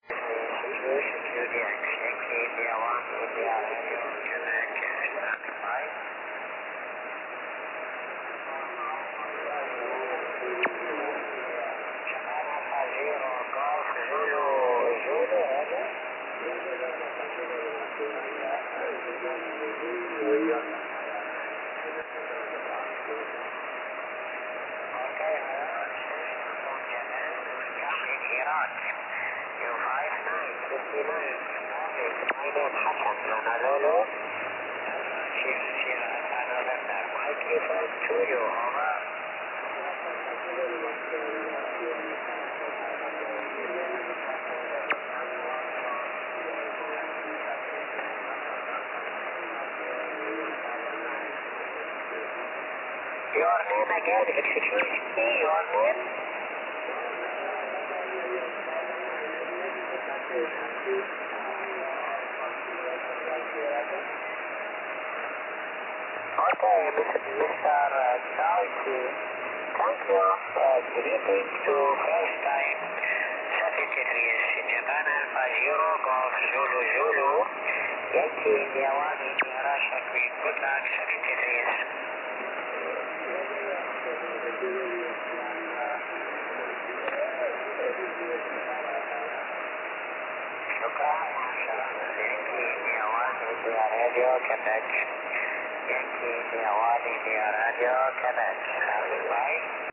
14.275MHz SSB